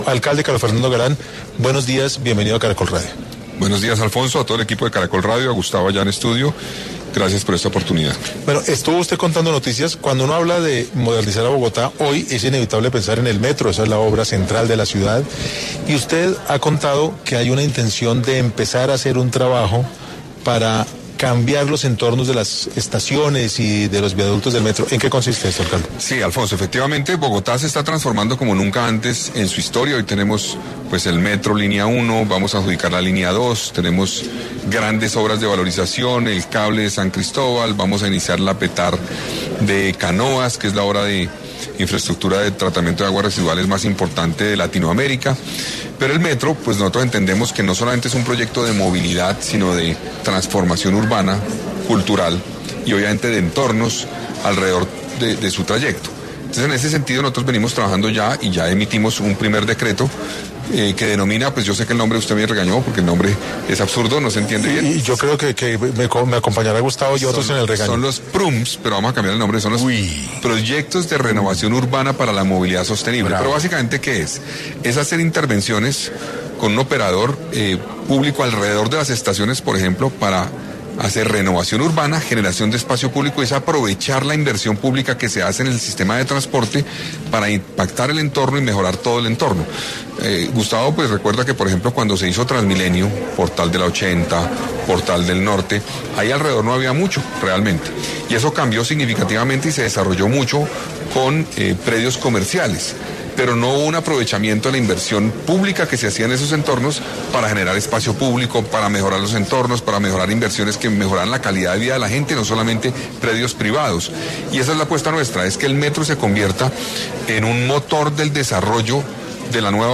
El alcalde de Bogotá, Carlos Fernando Galán habló en 6AM de los proyectos de infraestructura que hay en estos momentos en la capital
En medio de la primera Cumbre Bogotá Moderna de Prisa Media que se adelanta en la Universidad EAN habló el alcalde de Bogotá, Carlos Fernando Galán sobre las obras de modernización e infraestructura que se adelantan en la capital, entre ellas el metro